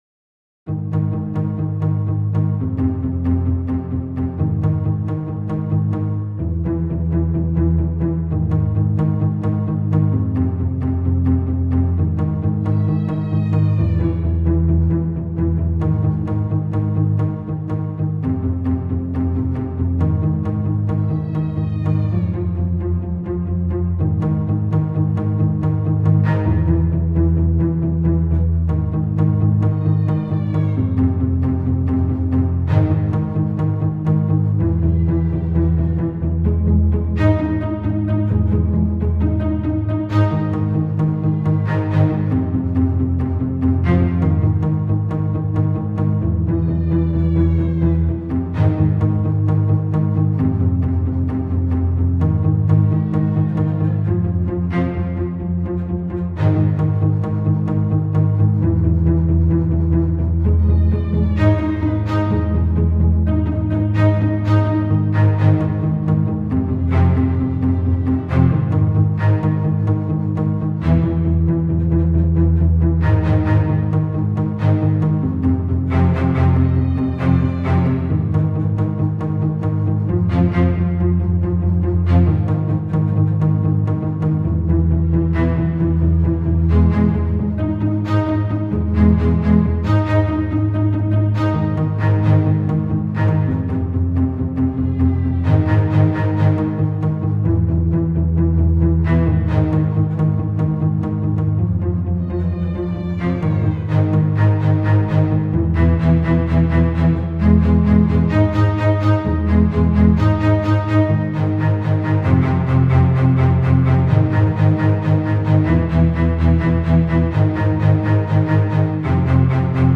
This is what I do when I’m sick and stuck inside for two and a half days: make weird music on my iPad.
The tracks aren’t in perfect time. They were recorded independently, each in one take.